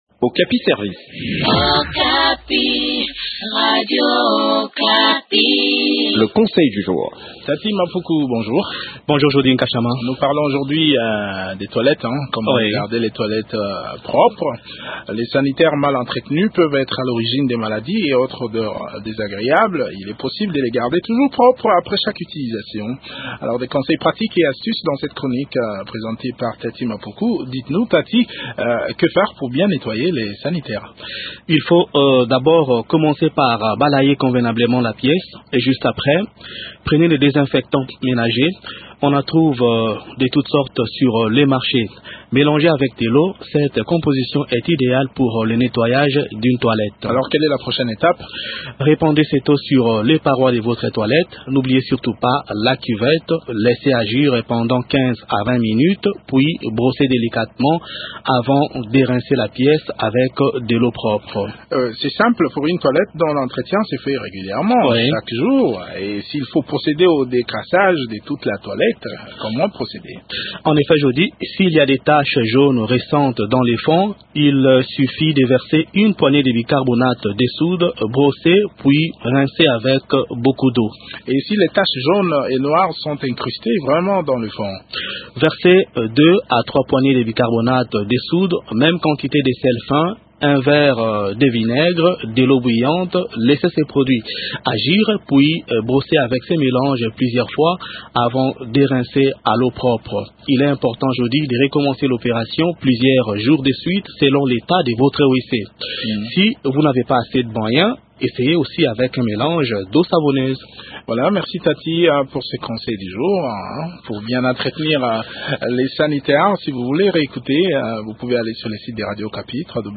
Des conseils pratiques et astuces dans cette chronique